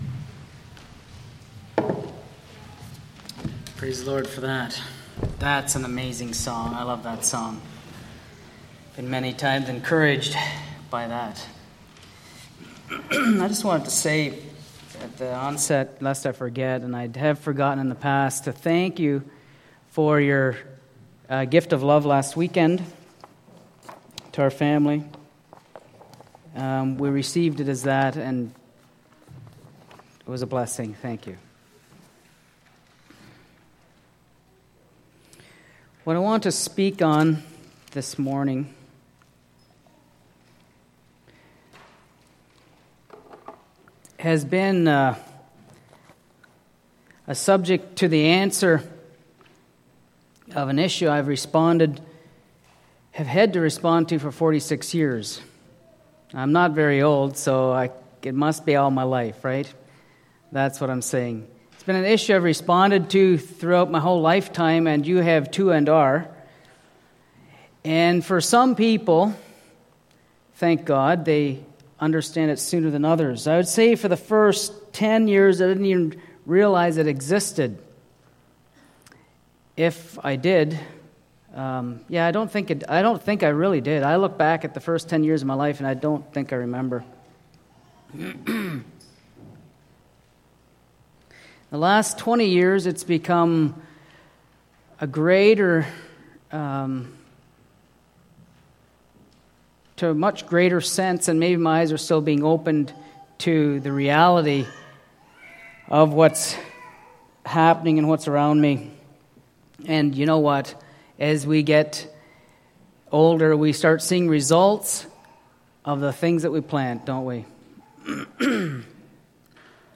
Sunday Morning Sermon Service Type